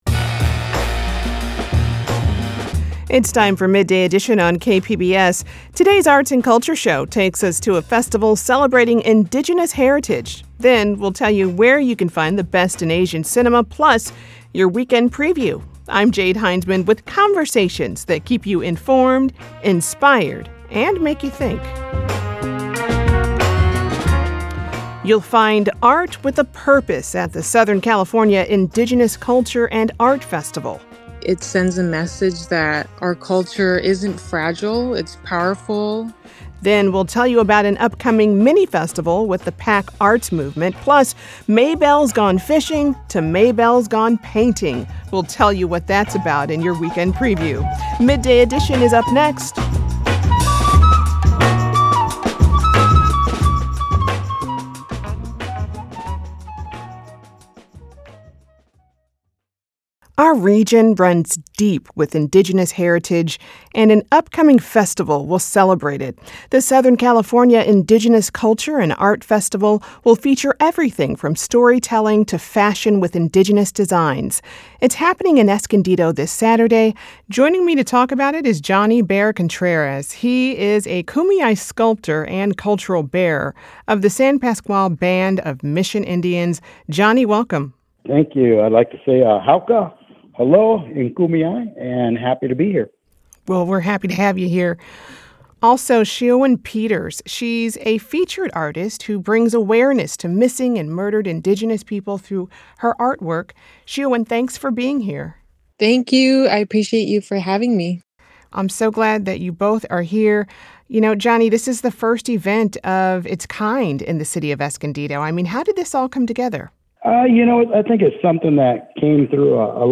Guests share diverse perspectives from their expertise and lived experience.